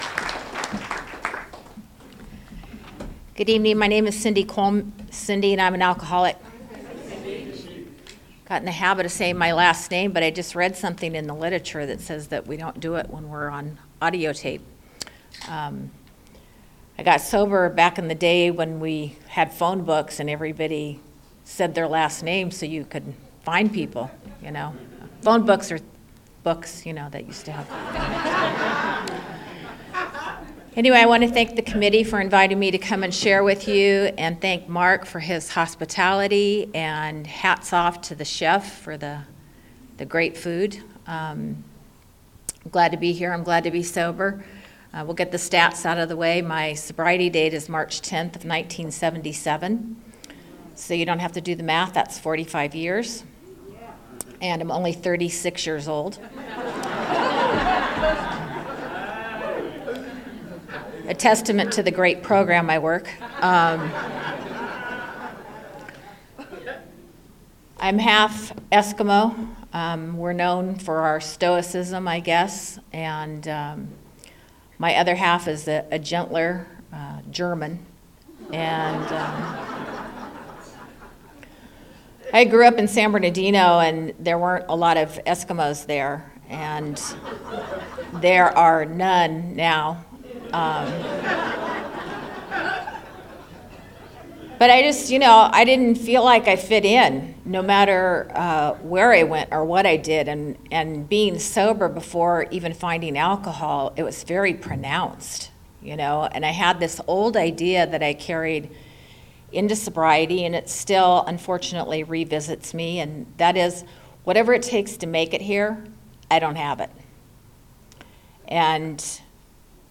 49th Annual Antelope Valley Roundup - Palmdale